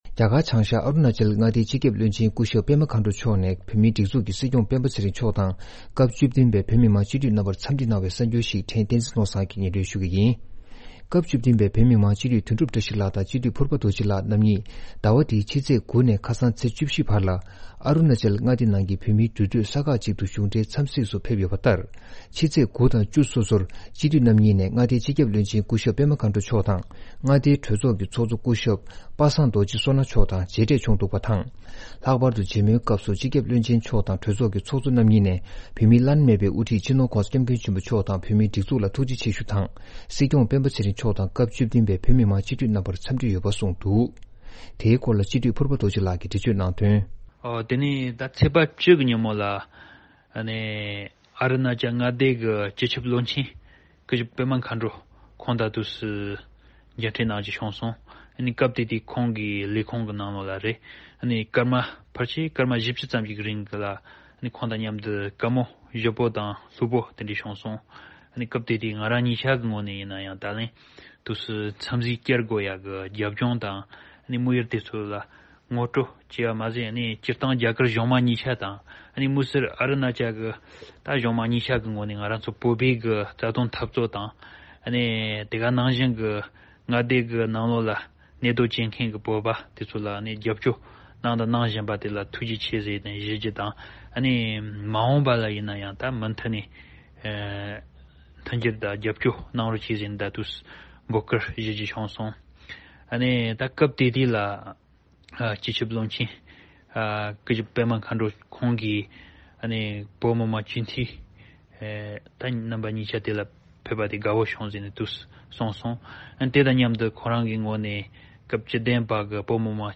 སྙན་སྒྲོན་ཞུས་ཡོད།